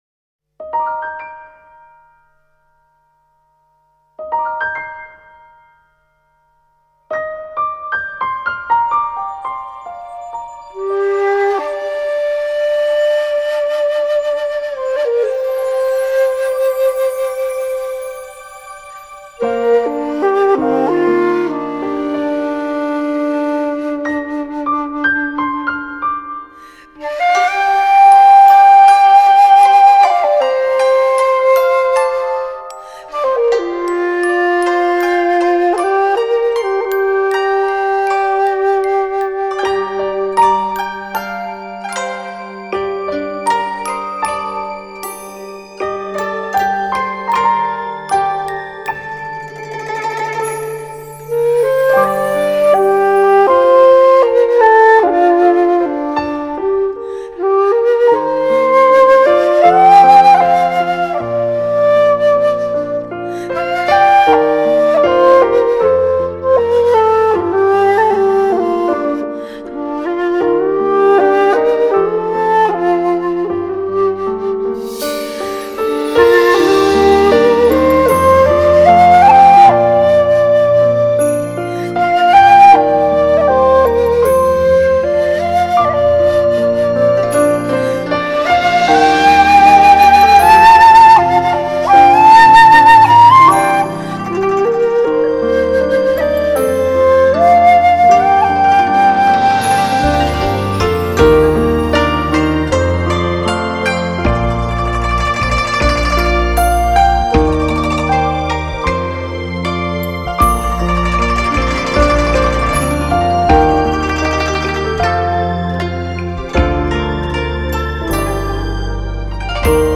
2周前 纯音乐 6